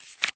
card_deal_4.ogg